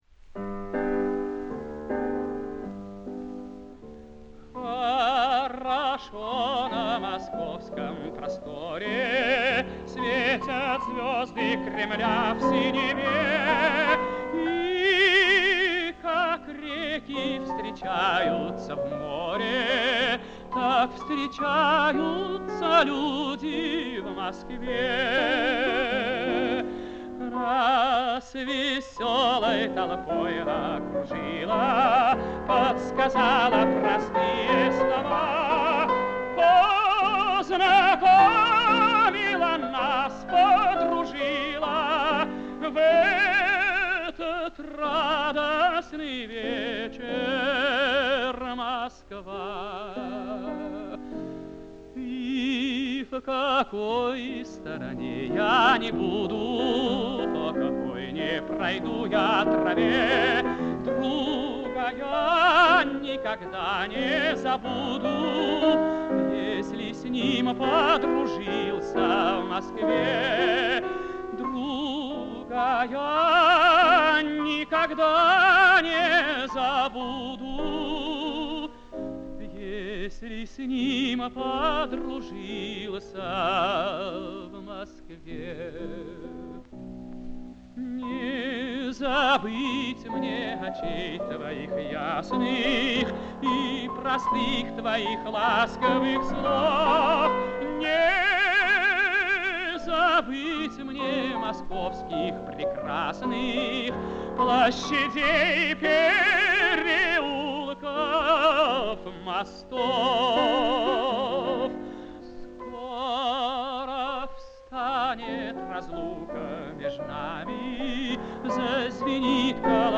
Какие были голоса!...